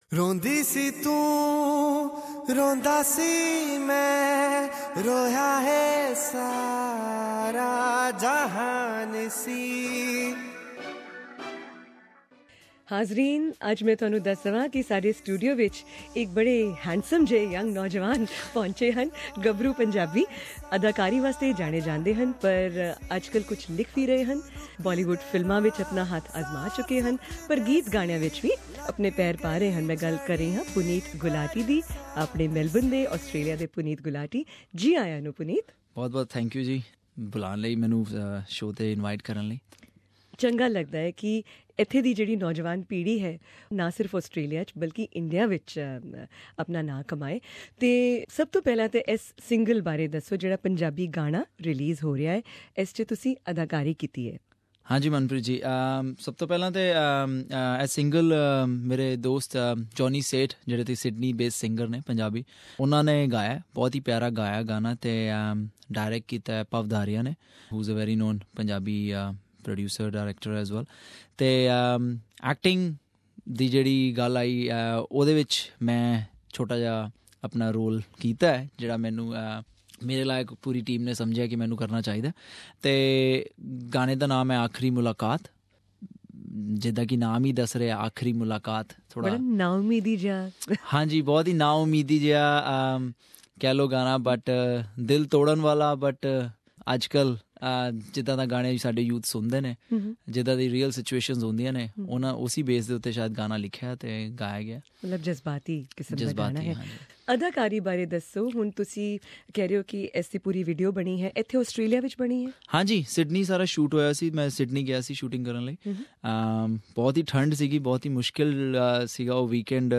Here are two interviews